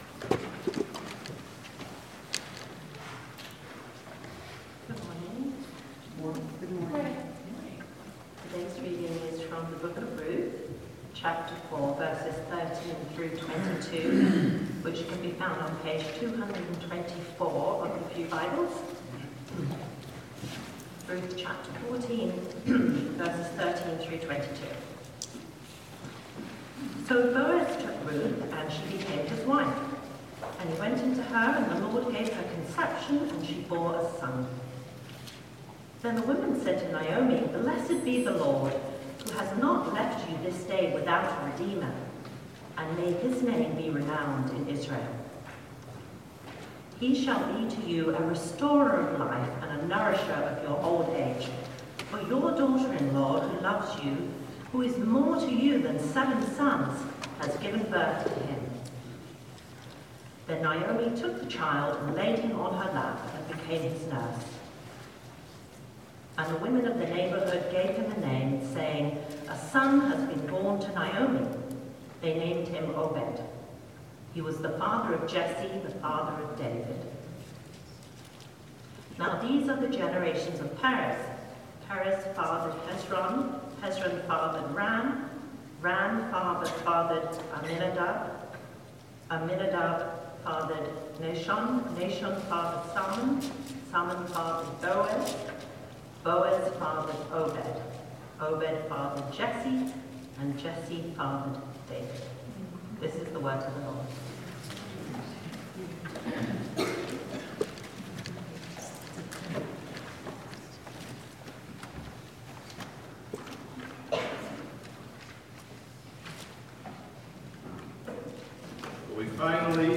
Passage: Ruth 4:13-22 Sermon